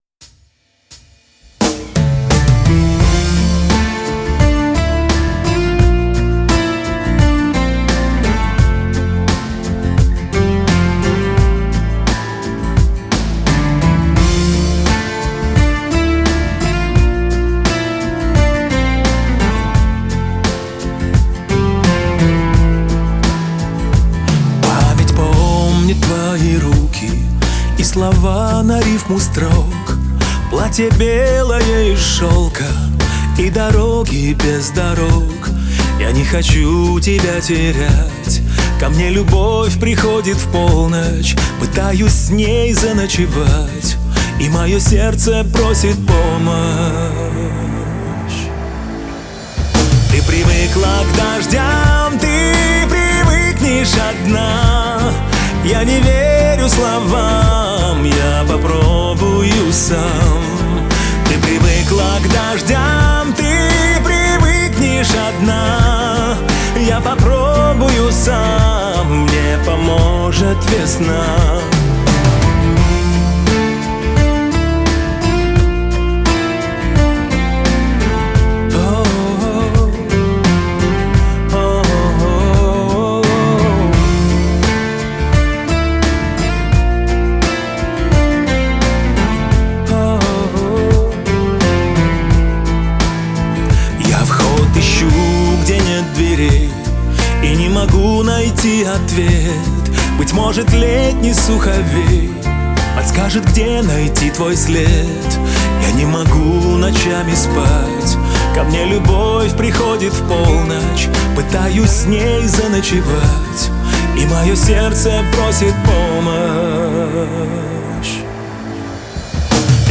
Категории: Русские песни, Эстрада, Поп.